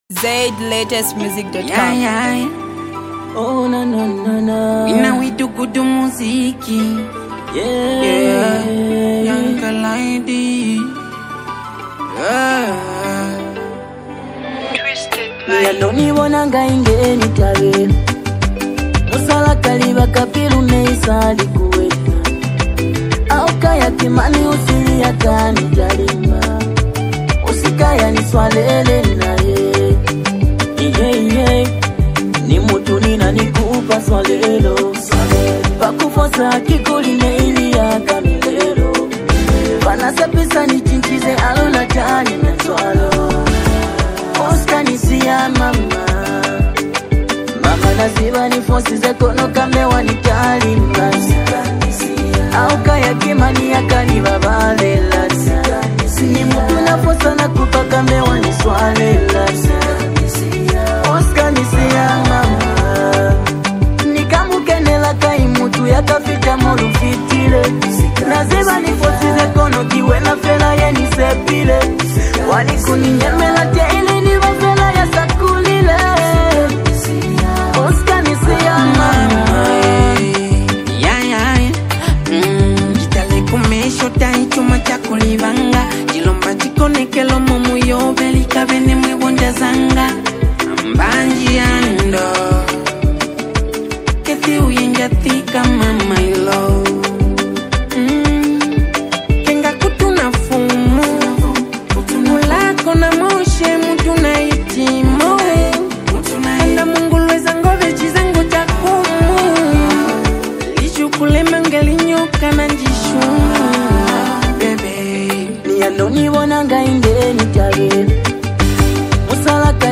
Mbunga artist, singer and song writer